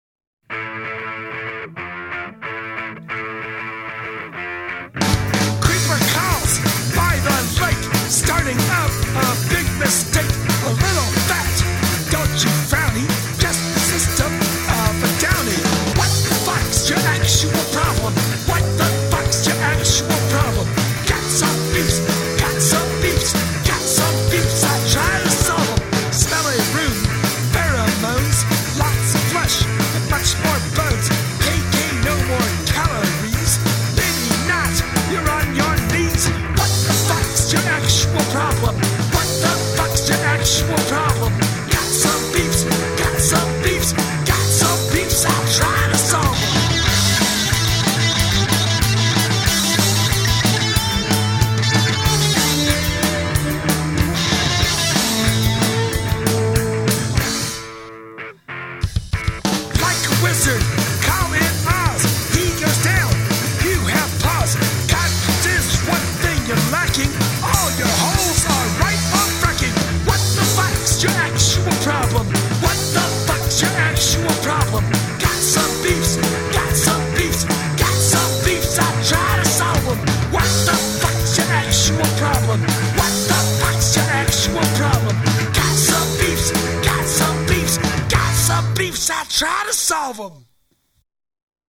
I sorta brickwalled the compression to prove I could.
guitars